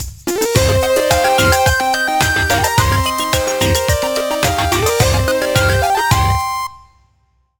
retro__musical_stinger_01.wav